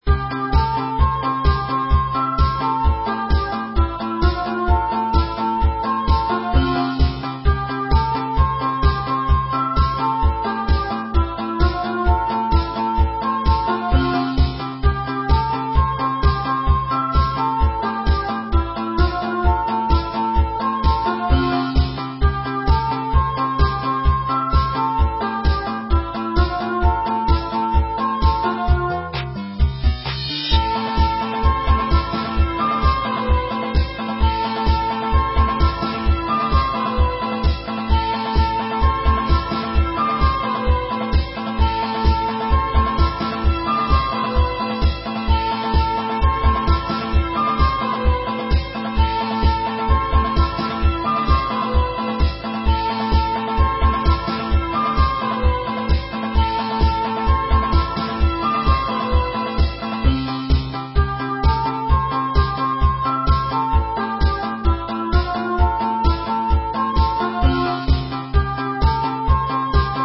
• Жанр: Танцевальная
Euro-pop. 2001.